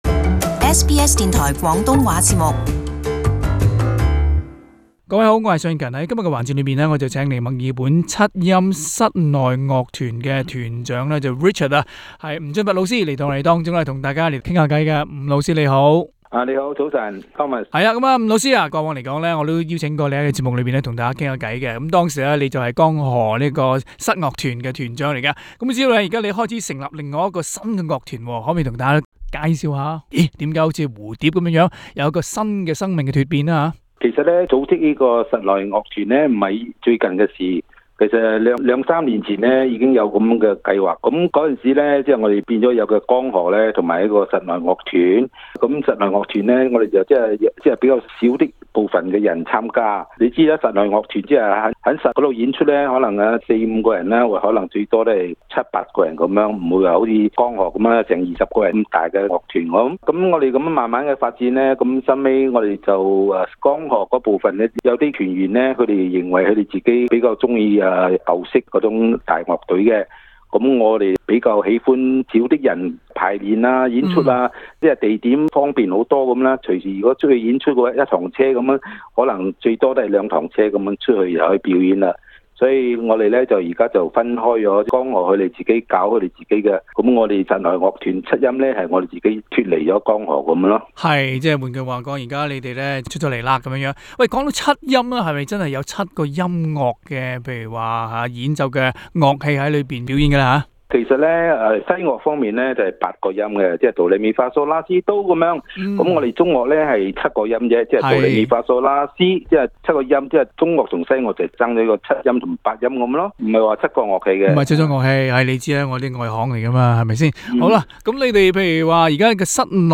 【社區專訪】墨爾本七音室内樂團奏出鄉土情懷